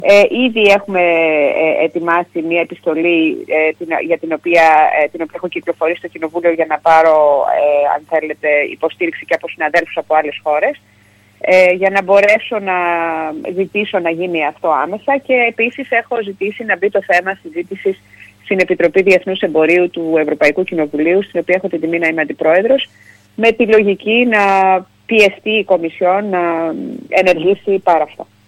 Μιλώντας σήμερα στην ΕΡΤ Ορεστιάδας η  Ευρωβουλευτής τόνισε ότι αυτό αποτελεί μια δική της πρωτοβουλία εκμεταλλευόμενη το χρονικό διάστημα που αναμένει απάντηση από την ΕΕ, μετά το πρώτο της σχετικό διάβημα και μέχρι να βγει η σχετική απόφαση.